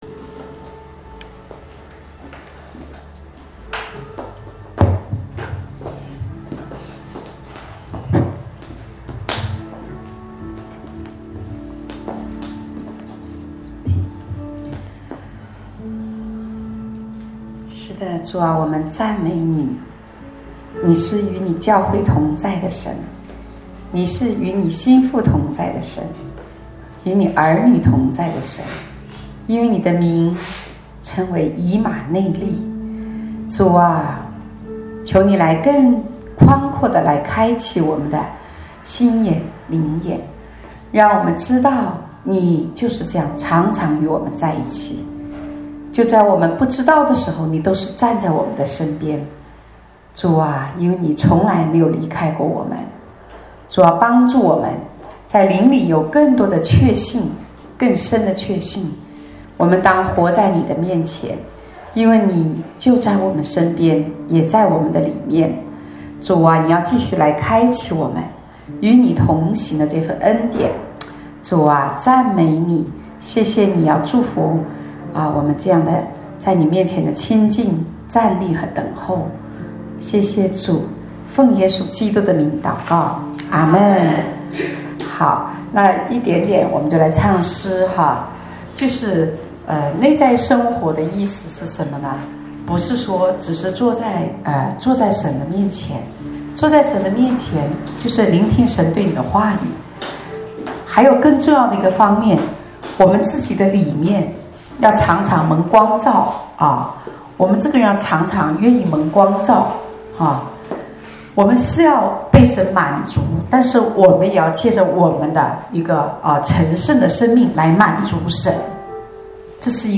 主日恩膏聚会录音